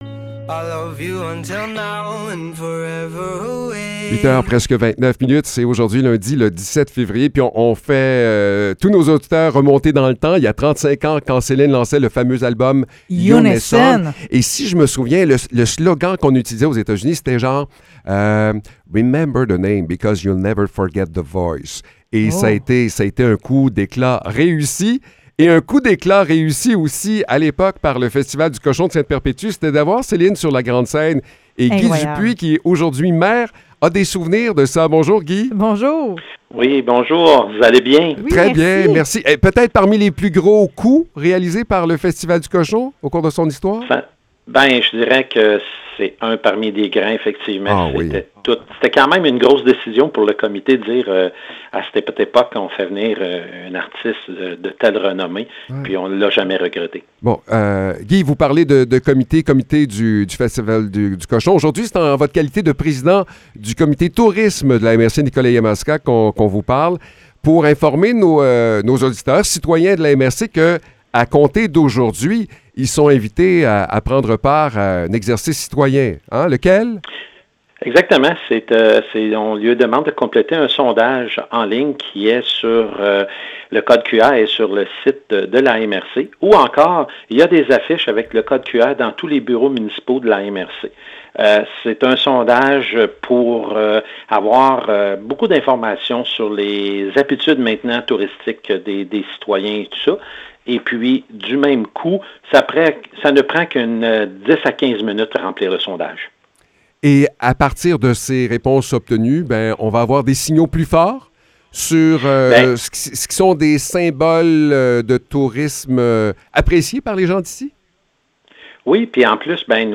Entrevue avec le maire de Ste-Perpétue